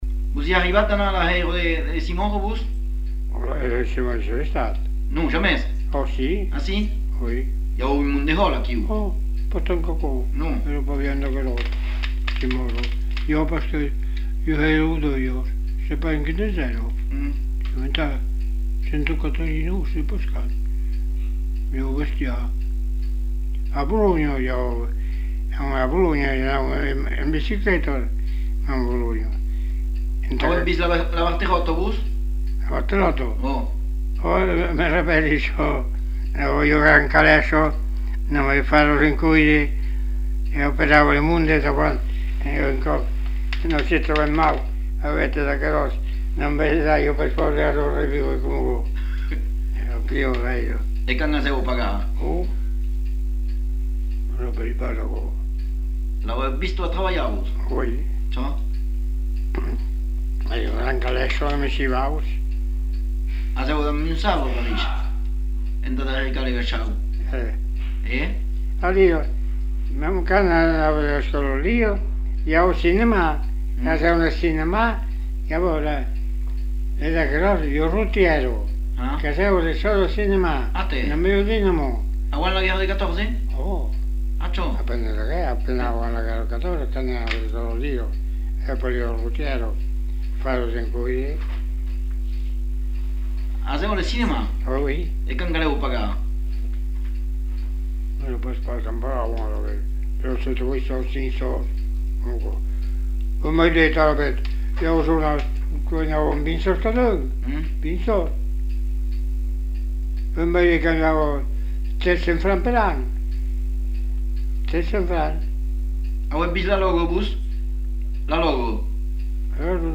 Lieu : Espaon
Genre : témoignage thématique